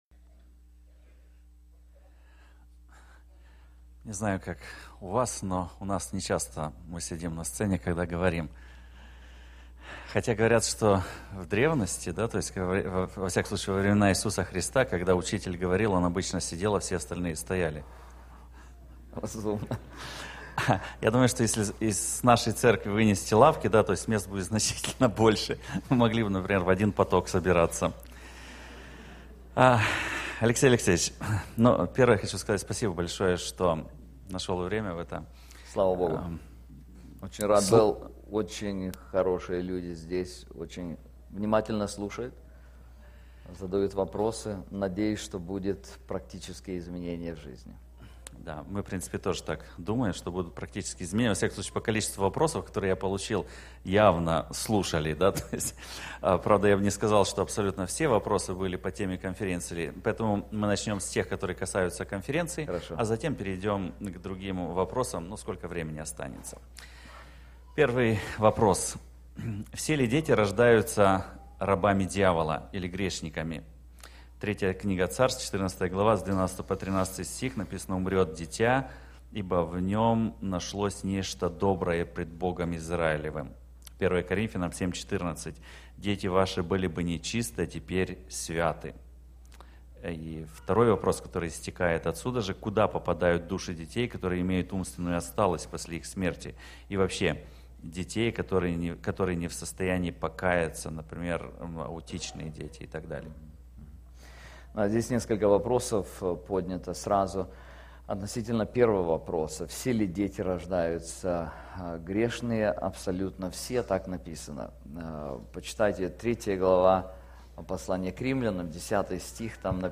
Вопросы и ответы